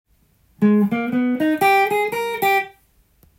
G7を例にフレーズを作ってみました。
④は③同様オルタードスケールを使用しています。
最初の音がA♭なのでG7の代理コードA♭ｍＭ７のような
玄人フレーズに聞こえます。